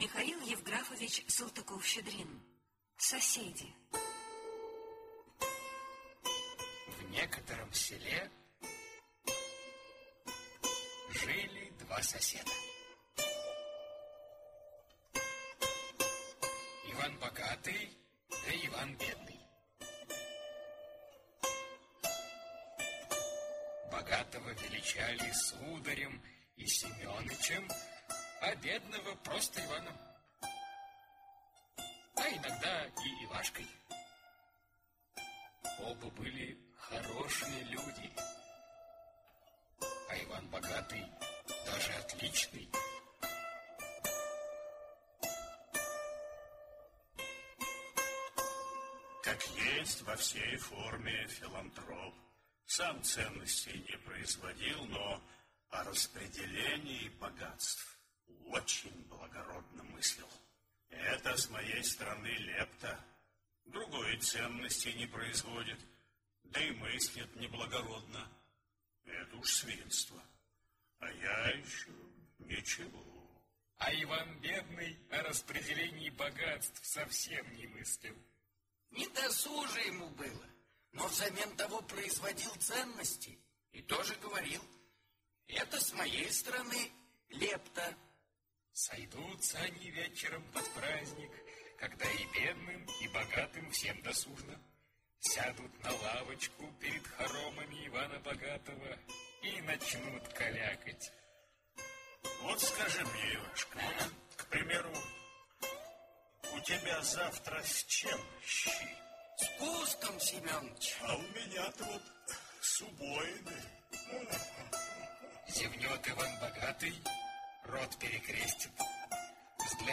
Соседи - аудиосказка Михаила Салтыкова-Щедрина - слушать онлайн